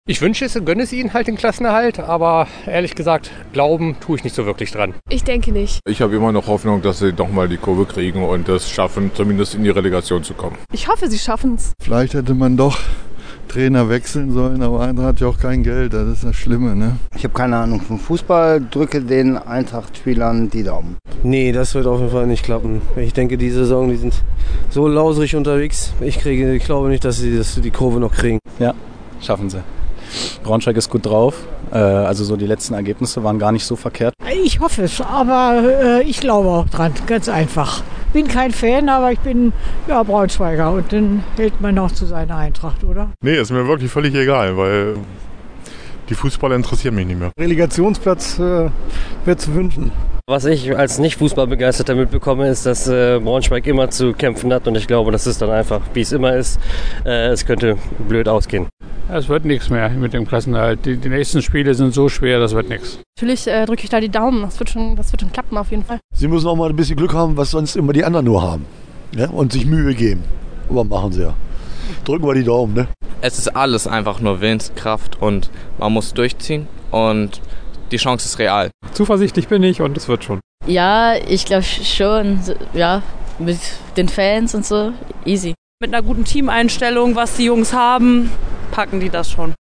Die Okerwelle-Umfrage: Schafft Eintracht Braunschweig noch den Klassenerhalt?
Ist der Klassenerhalt noch machbar – ja oder nein? Genau das haben wir einige Passanten in der Braunschweiger Innenstadt gefragt.